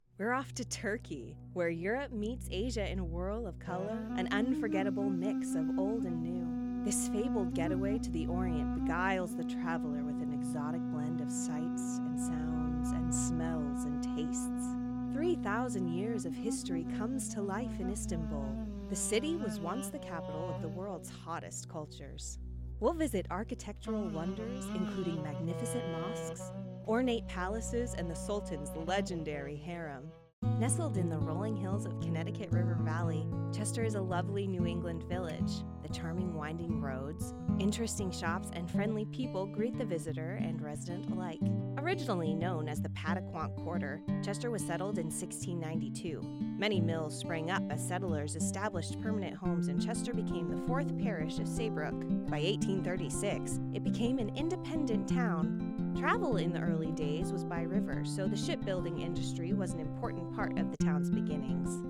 Playing age: Teens - 20s, 20 - 30sNative Accent: North AmericanOther Accents: American, Australian, Irish, London, RP, Scottish
• Native Accent: American Standard, Texan